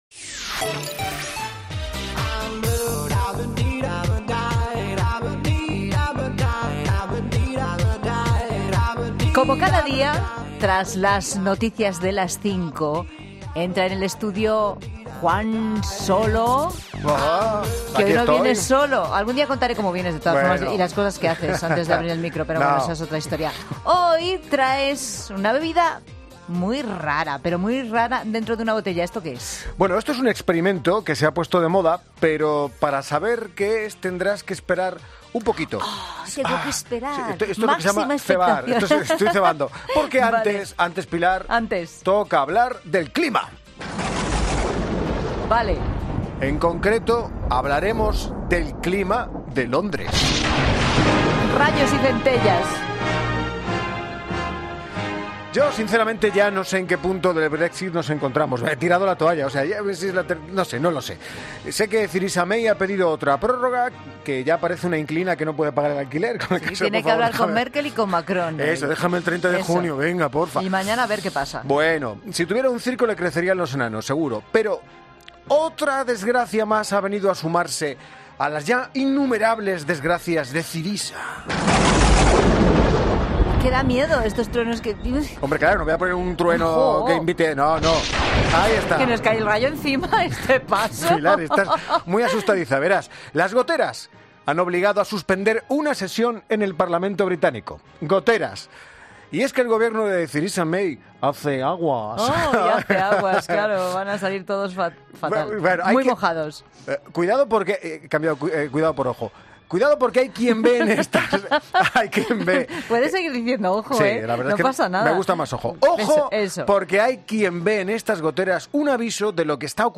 Daremos un giro a la actualidad informativa y además, te sacaremos una sonrisa. Hoy hablaremos del clima de Londres y experimentaremos con dos bebidas en el estudio